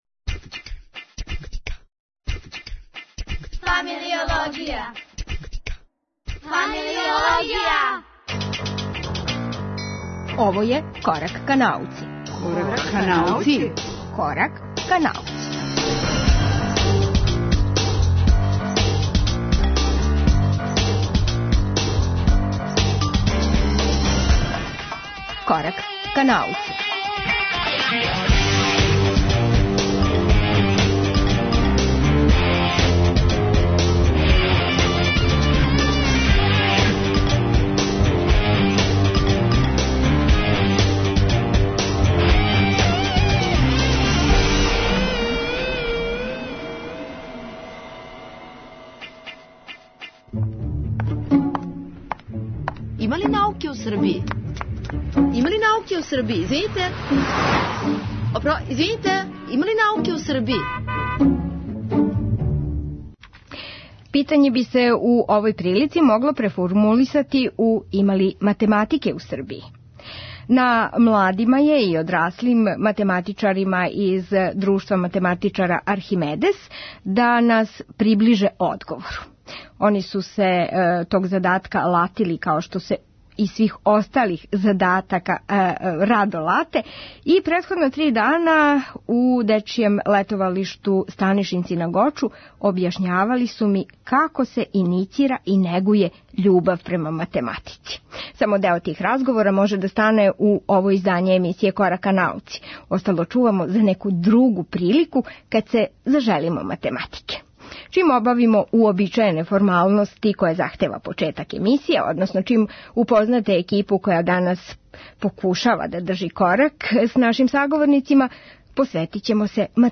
Настављајући циклус Има ли науке у Србији, придружили смо им се на Гочу у дечијем летовалишту Станишинци. Неке од разговора вођених током три дана поделићемо са слушаоцима емисије Корак ка науци.